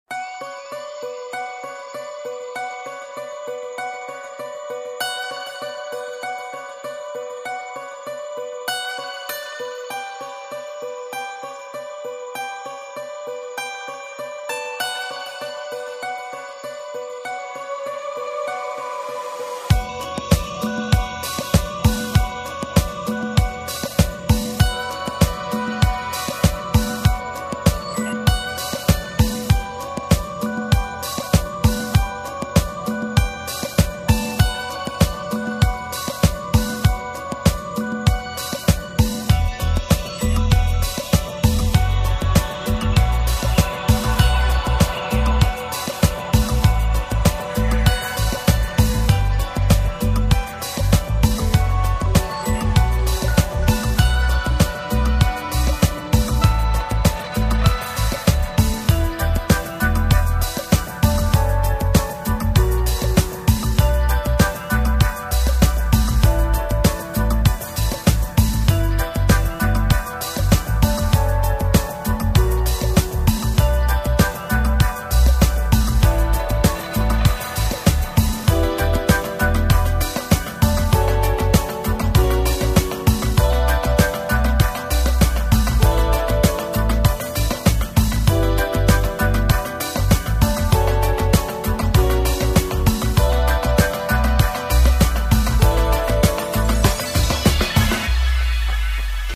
Спокойный будильник в стиле Downtempo